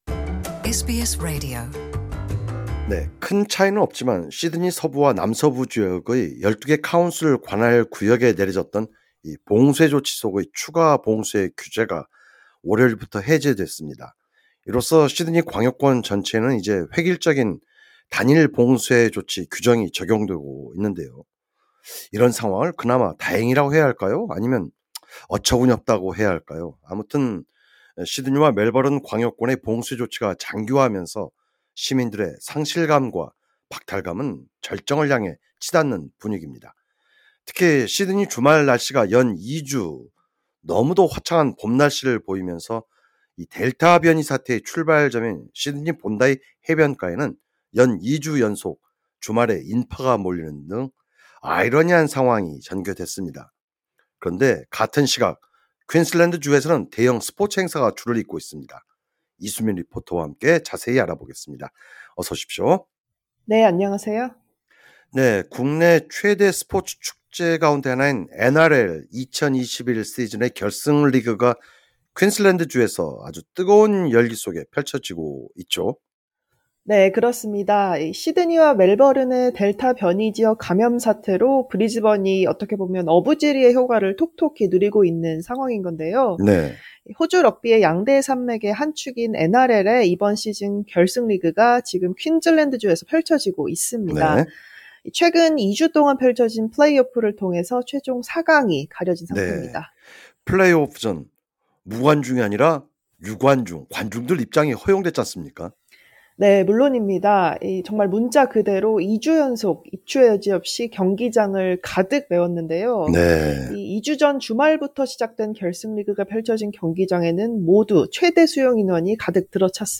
진행자: 플레이오프전은 관중들 입장이 허용된 거죠?